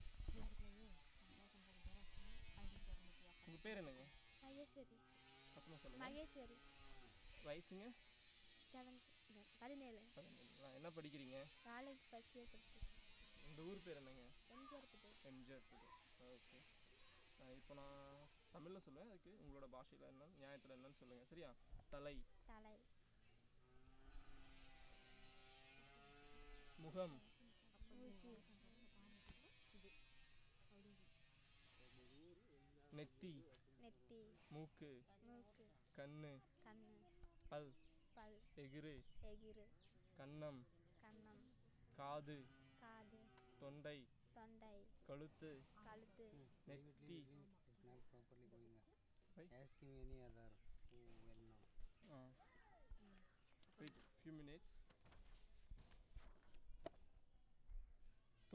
Elicitation of words of human body parts